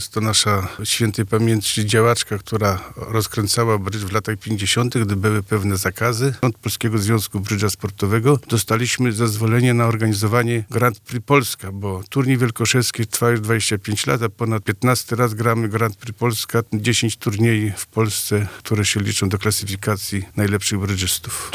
gosc dnia brydz 2.mp3